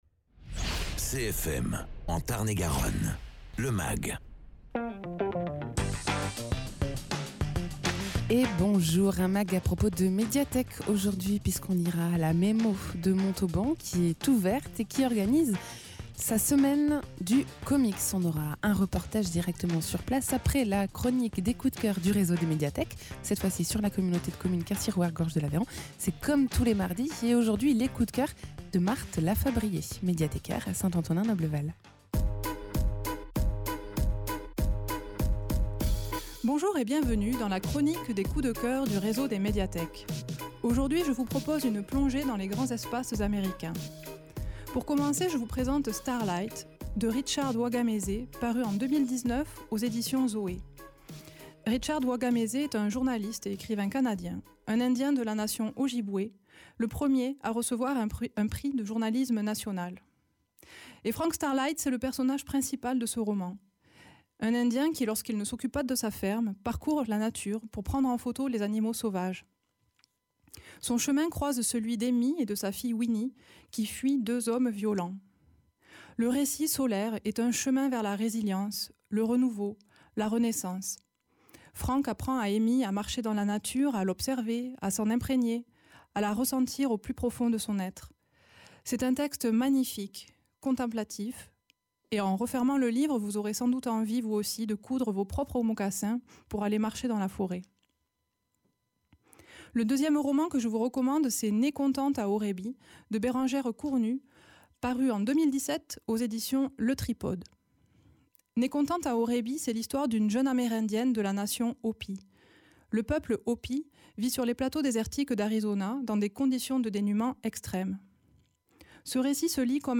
Reportage lors de la semaine du Comics à la Médiathèque de Montauban.